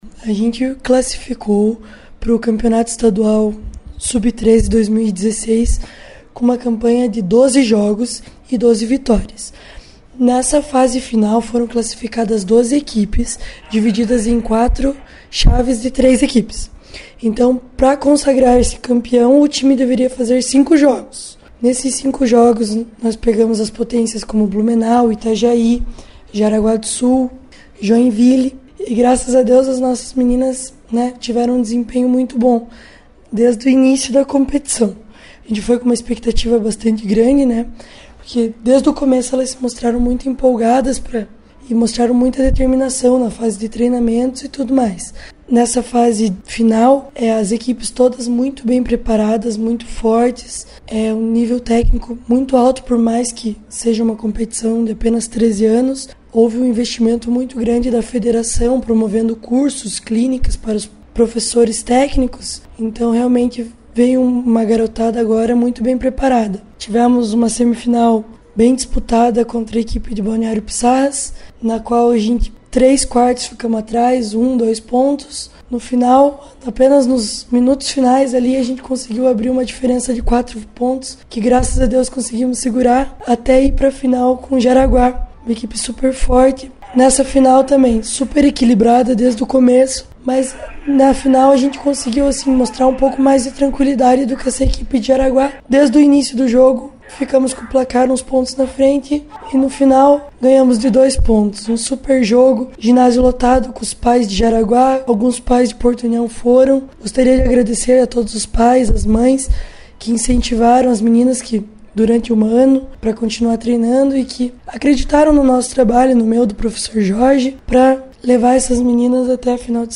em entrevista para a Rádio Colmeia.